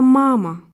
La syllabe accentuée a une prononciation plus longue et plus appuyée que les autres.
мама [e muet][1], тётя [е][6]